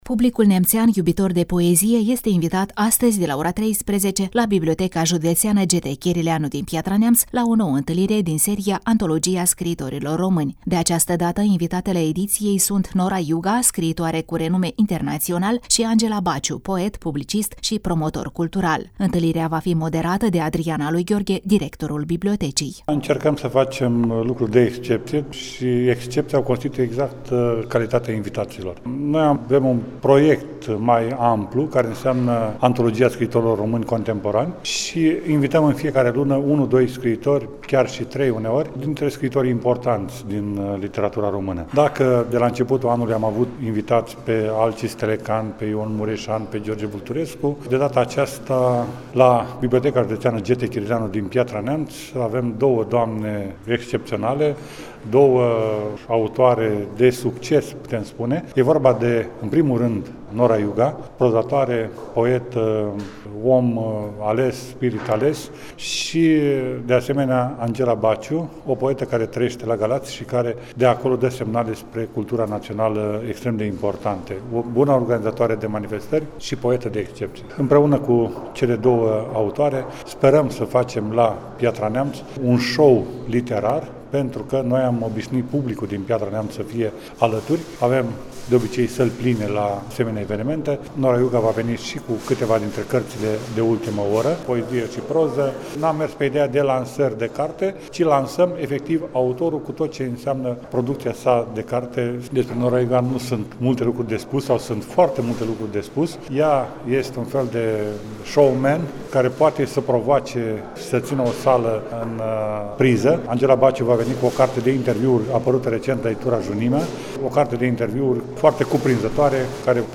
Reportaj cultural